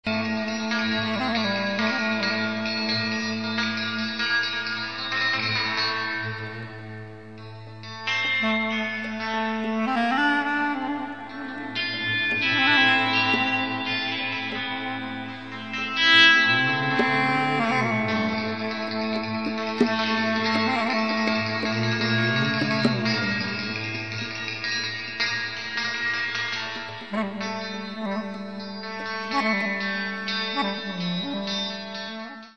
Woodwinds, tribal drums and an underlying ethereal sound
Loud, heartfelt and slightly wicked
tribal-ethereal-instrumental-rock-and-roll